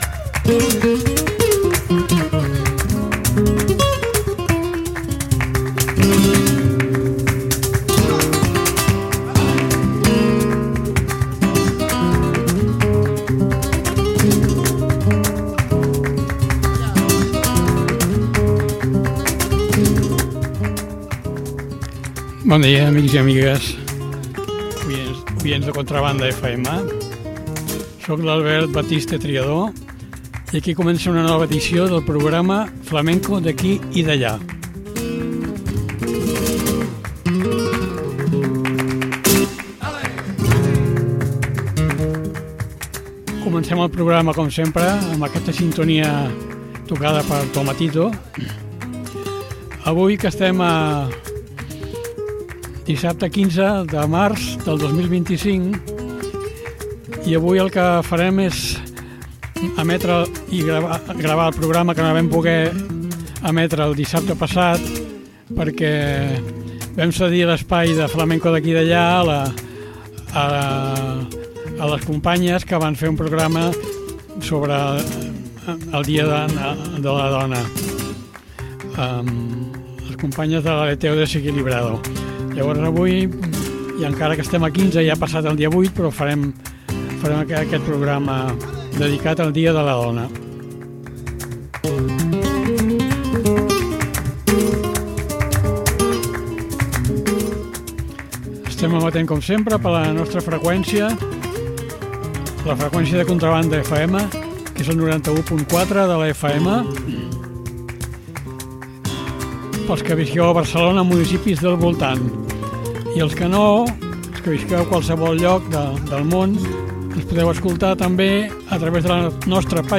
El flamenco avantguardista de Rosario la Tremendita.
Carmen Linares: Cantiñas, granaínas, tangos, taranta, bulerías i seguiriyas.